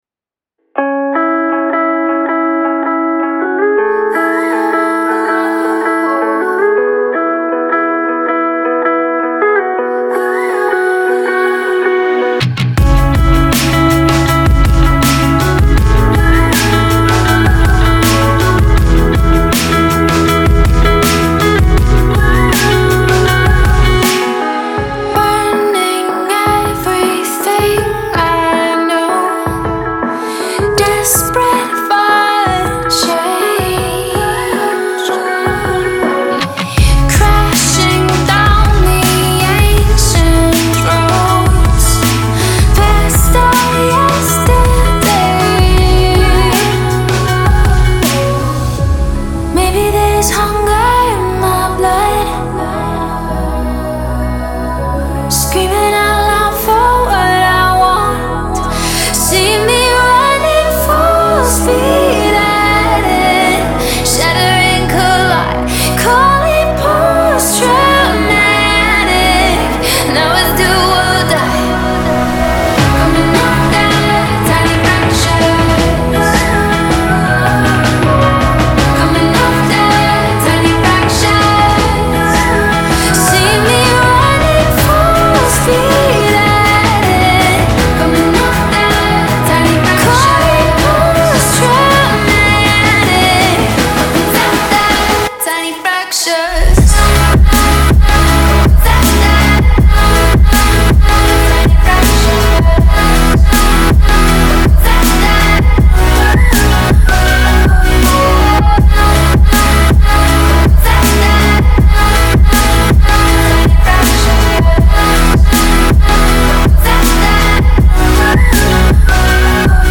это трек в жанре EDM